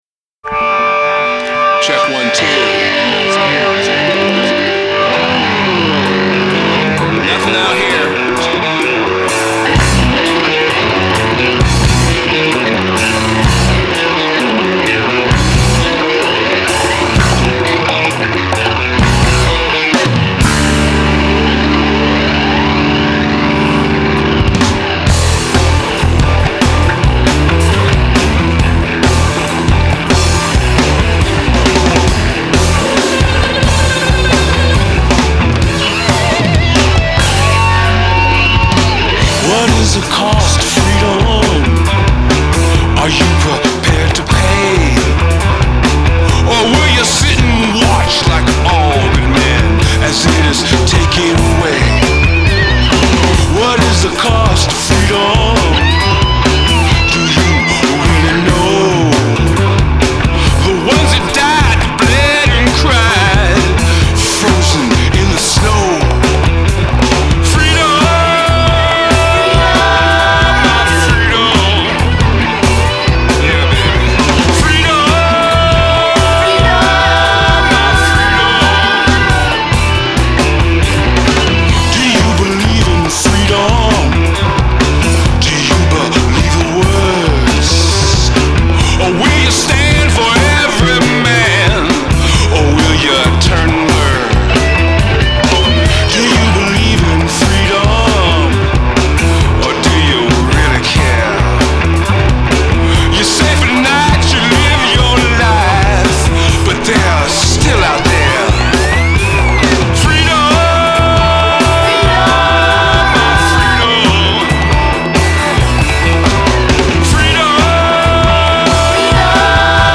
Жанр: Blues-Rock